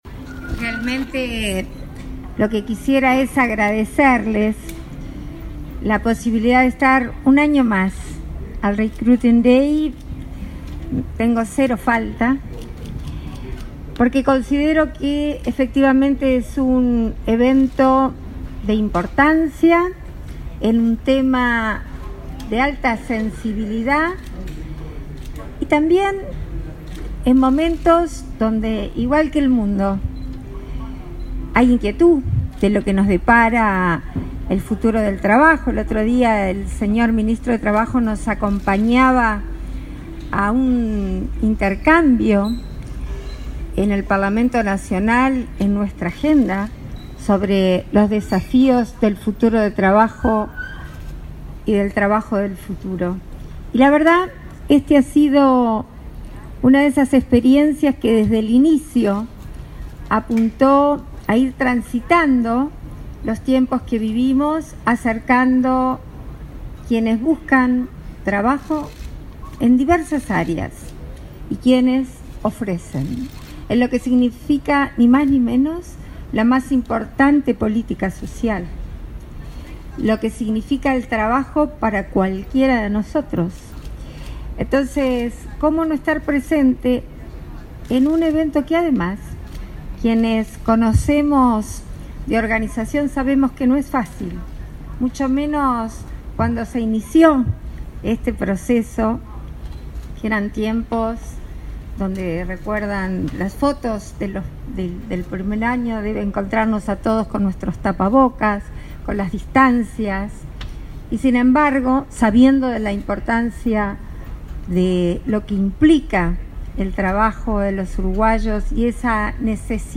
Palabras de autoridades en inauguración del Recruiting Day 2022